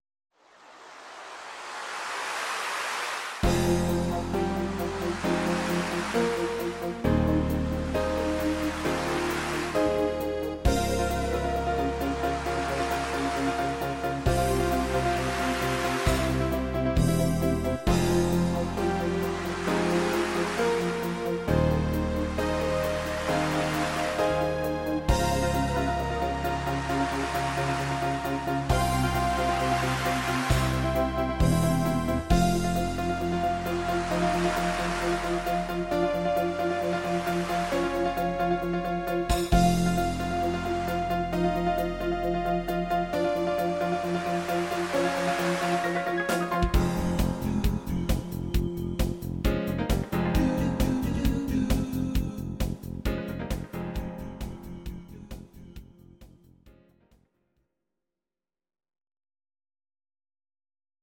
Audio Recordings based on Midi-files
Pop, Disco, 1980s